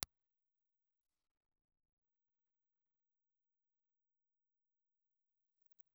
Impulse Response File:
Impulse Response file of the RCA 44BX ribbon microphone.
RCA_44BX_IR.aiff